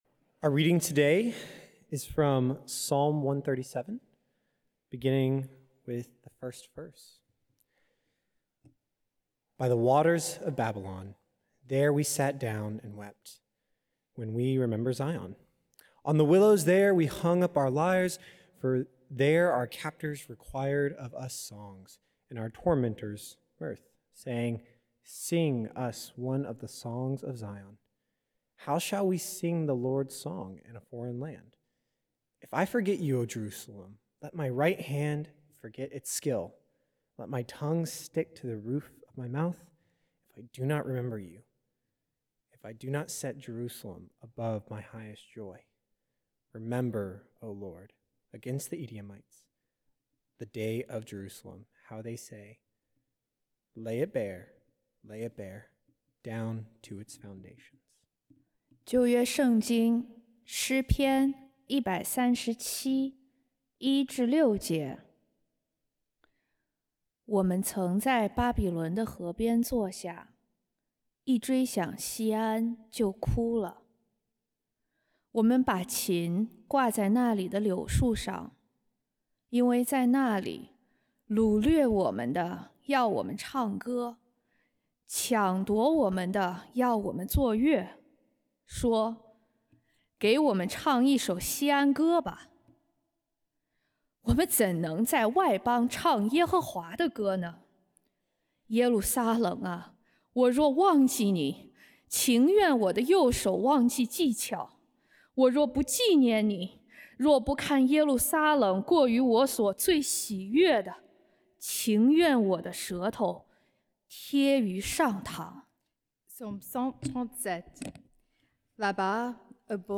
Beeson Divinity School Chapel Services